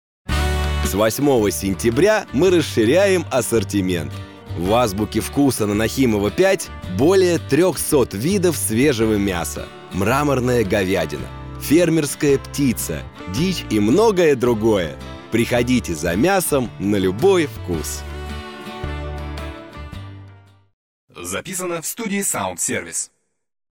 Мужчина
Среднего возраста
Бархатный
Низкий
3923_reklama.mp3